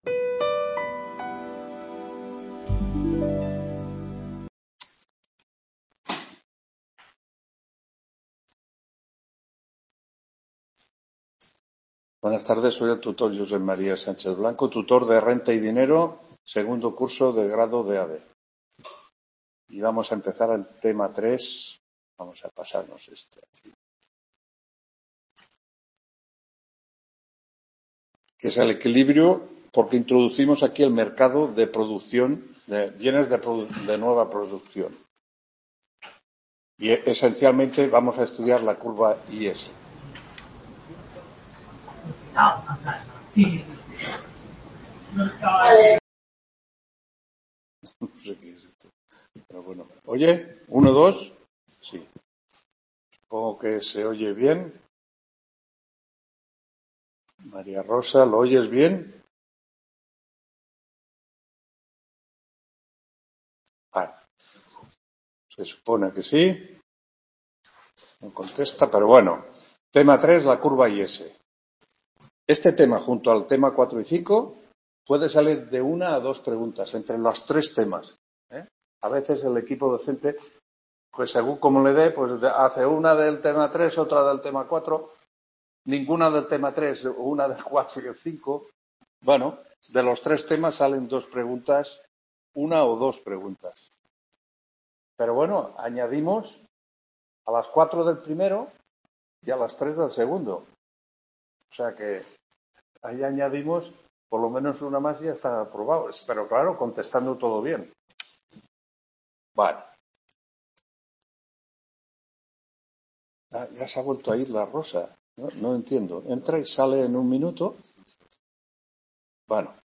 8ª TUTORÍA RENTA Y DINERO CURVA IS (nº 325)